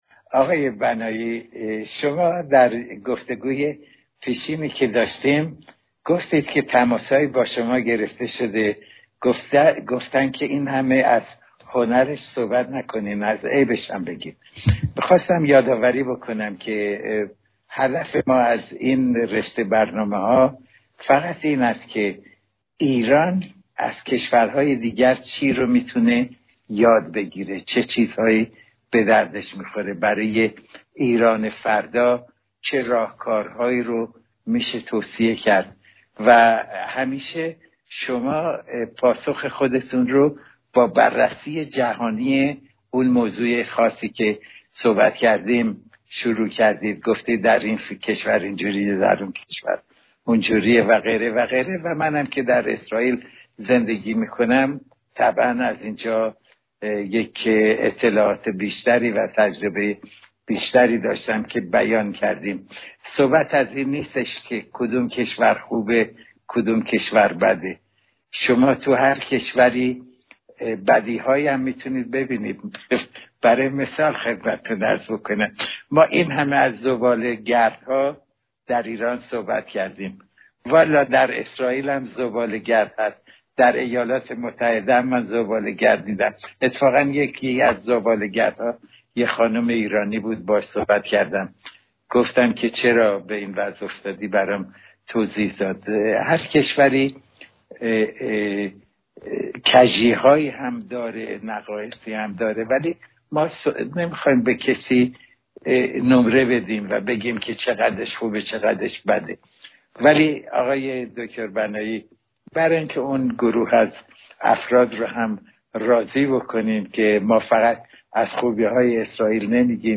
اکنون، پیاله سرا افتخار دارد یکی از شنیدنی‌ترین مکالمه های این دو جنتلمن را با مضمون بالا تقدیم نوشمندان باوفایش بکند.